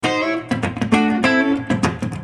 Free MP3 funk music guitars loops & sounds 1
Guitare loop - funk 23